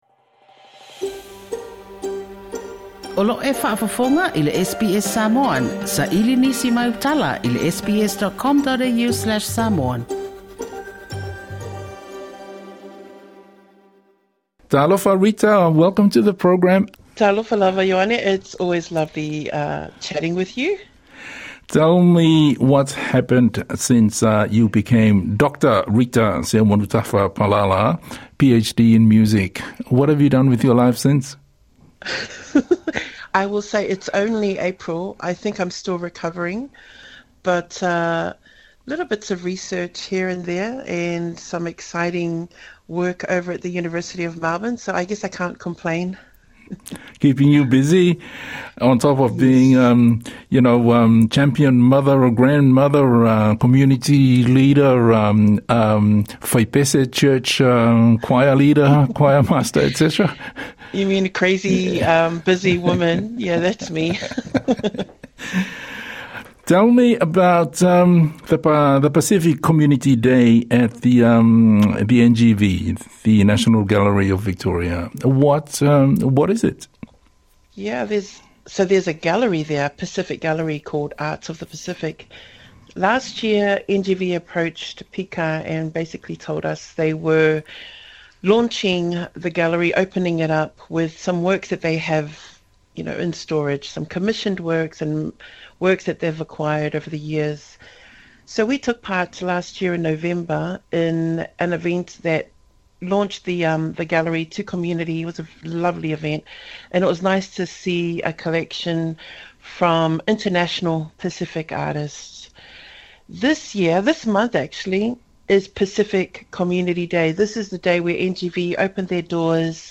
I se talanoaga